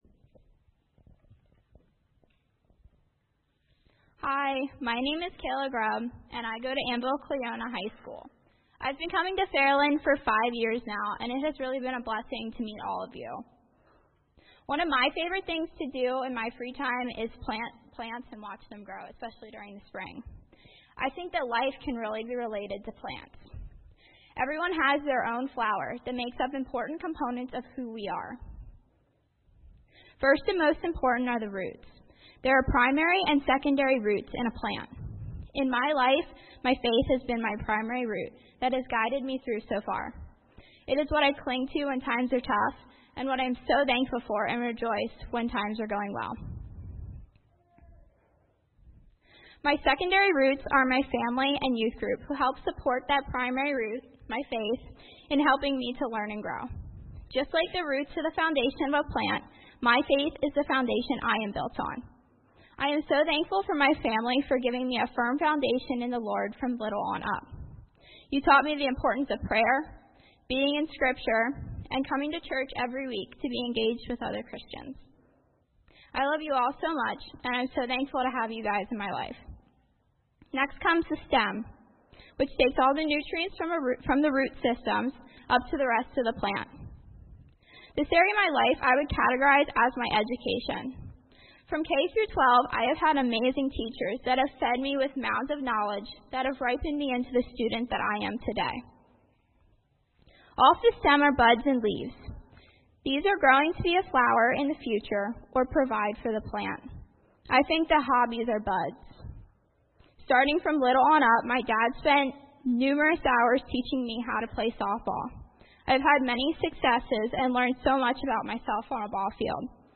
Sermons - Fairland Church
Youth-Sunday.mp3